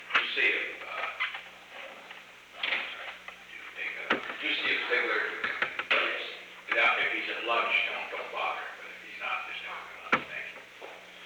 Secret White House Tapes
Conversation No. 735-10
Location: Oval Office
The President met with an unknown person.